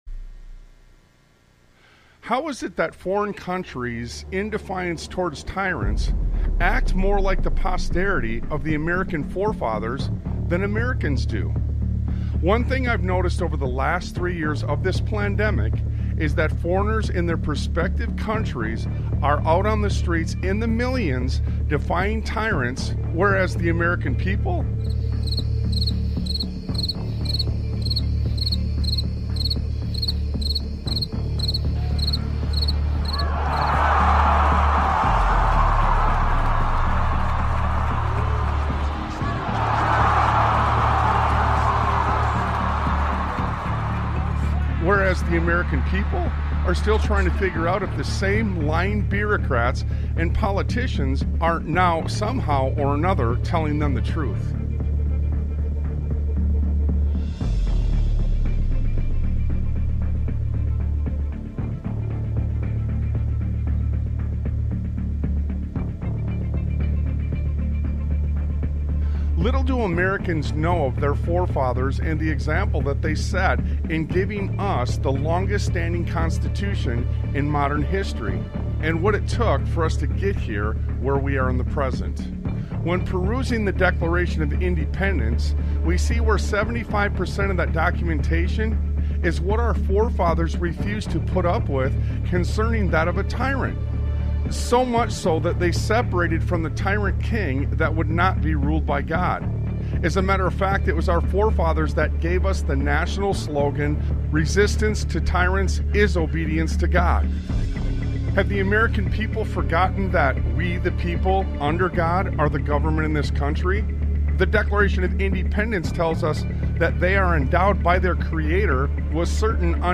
Talk Show Episode, Audio Podcast, Sons of Liberty Radio and A Push For War - You First on , show guests , about A Push For War - You First, categorized as Education,History,Military,News,Politics & Government,Religion,Christianity,Society and Culture,Theory & Conspiracy